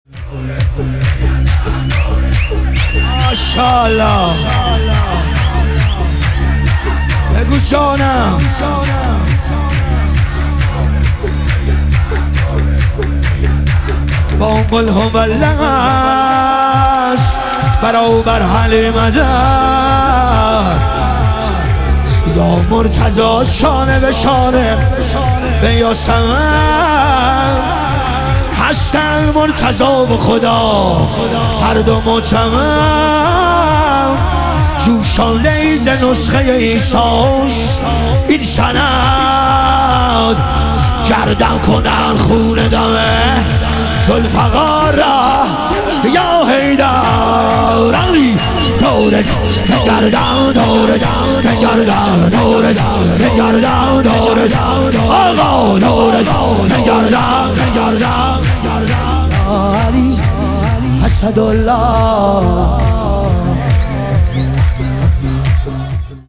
ظهور وجود مقدس حضرت رقیه علیها سلام - شور